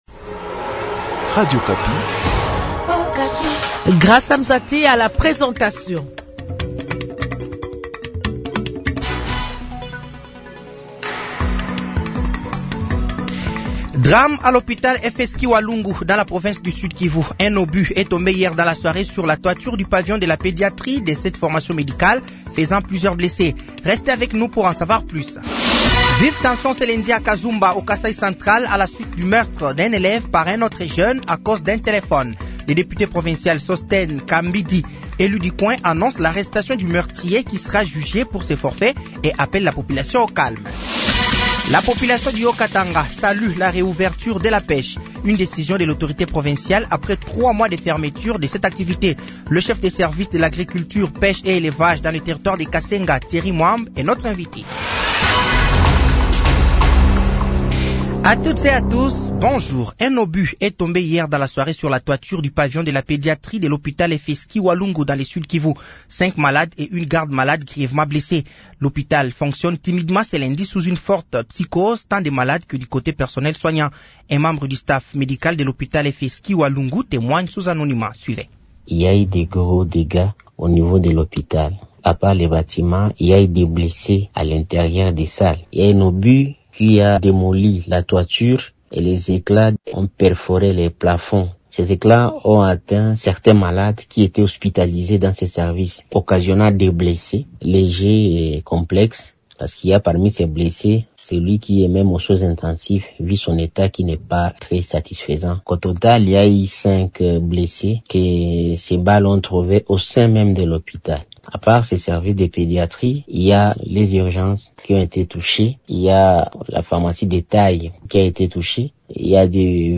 Journal de 15h
Journal français de 15h de ce lundi 10 mars 2025